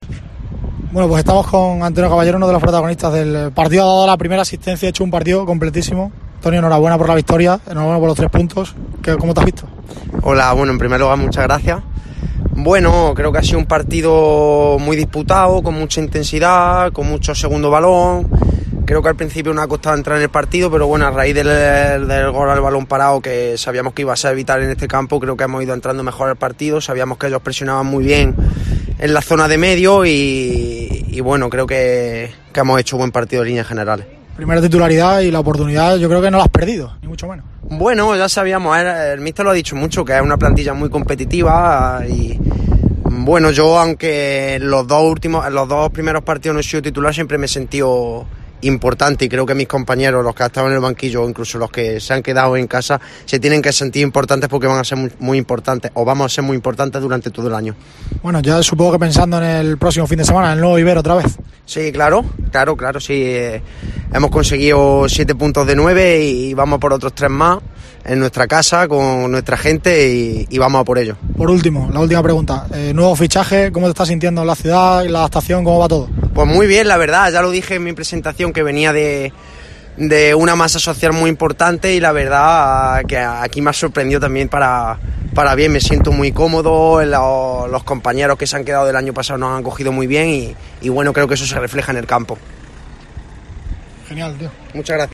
Entrevista
tras el partido en Cádiz